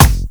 KICKSTACK1-L.wav